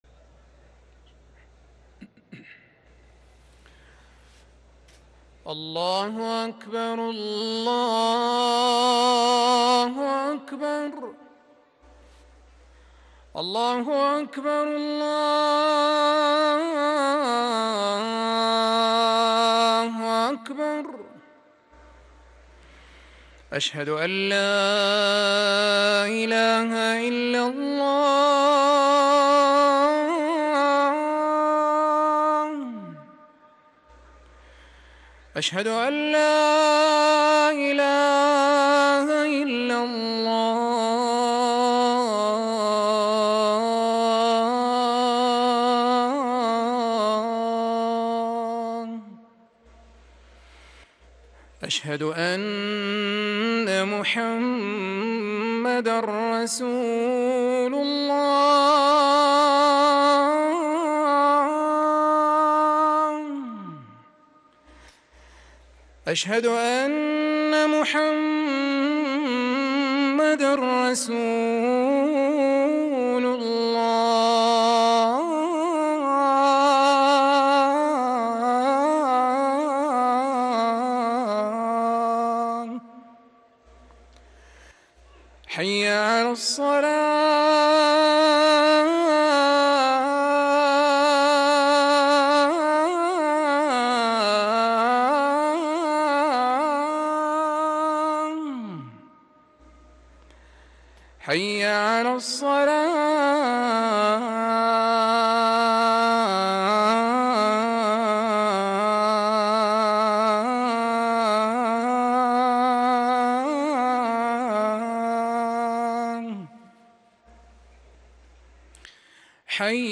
أذان العصر
ركن الأذان